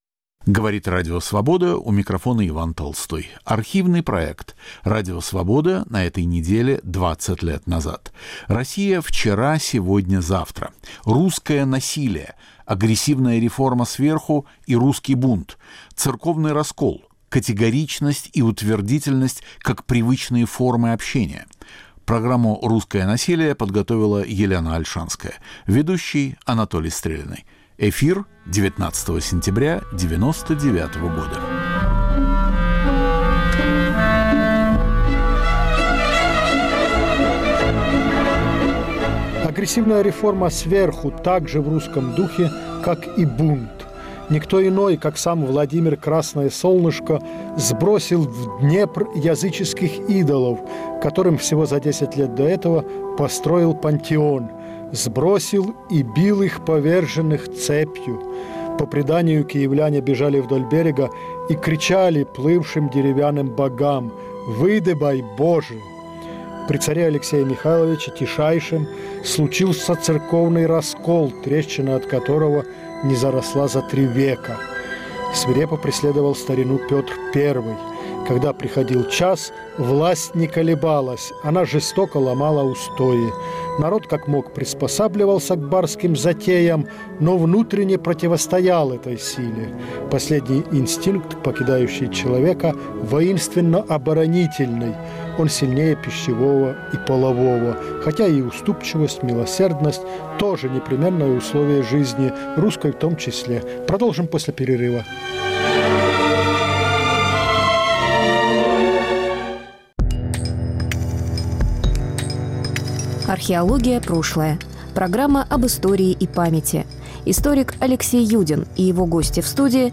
Могла ли Россия пойти другим путем? Радио Свобода на этой неделе 20 лет назад. Архивный проект.